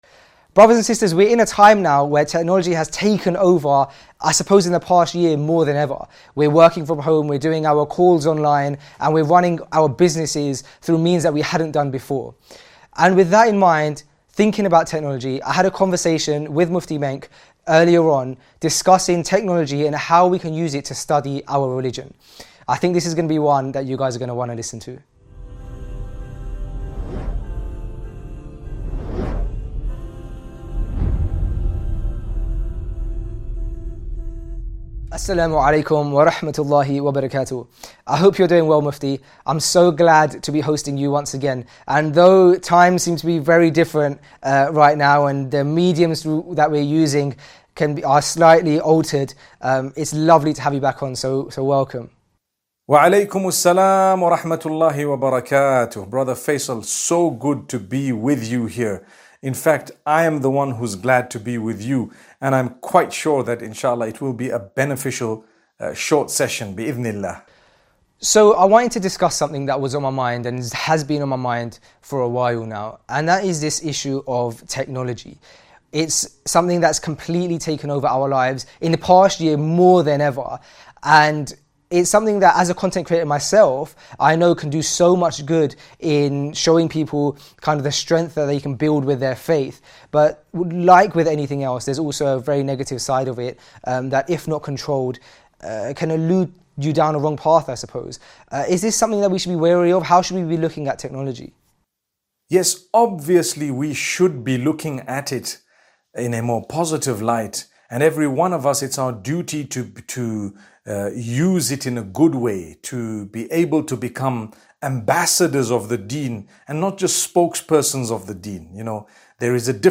A Conversation with Mufti Menk